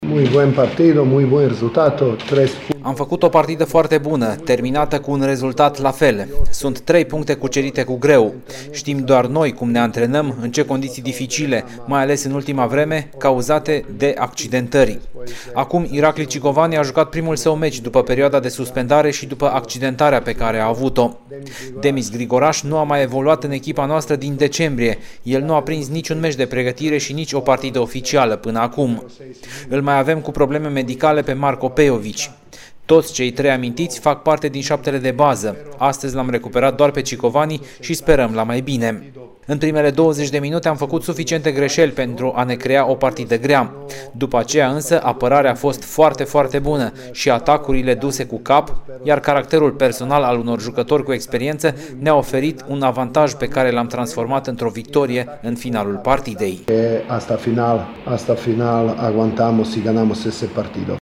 Declaraţiile finalului de meci: